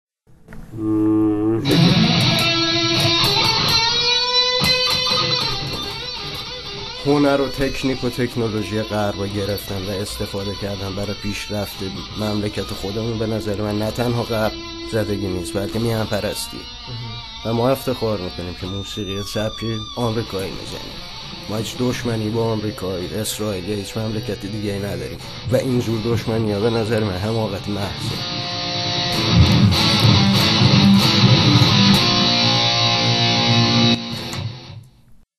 اینقدر تو اون نیمساعت حرفهای حسابی زدن که حرفهاشونو ضبط کردم برای روزهفتم.
گیتار